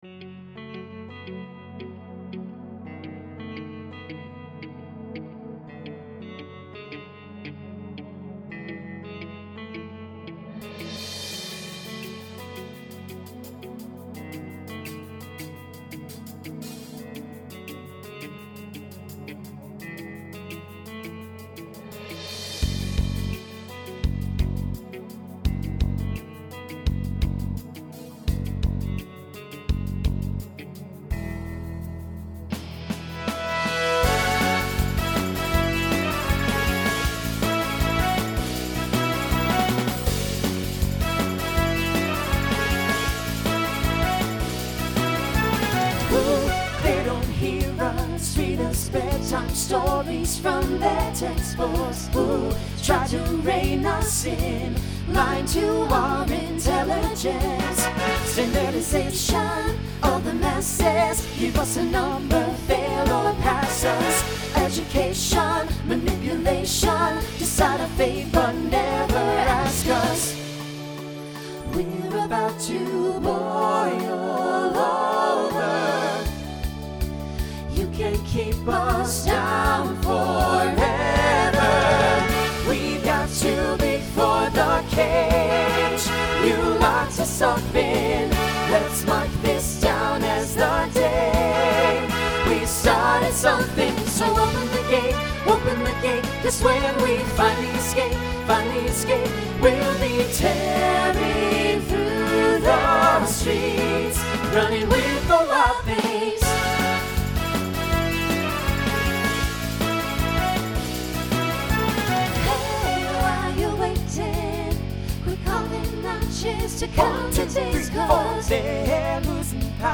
New SSA voicing for 2023.